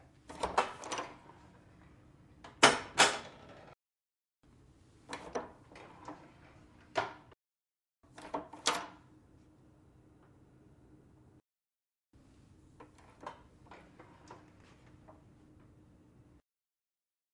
金属锁扣 锁扣金属
描述：金属闩锁闭锁金属
标签： 金属 金属 锁存器 锁存
声道立体声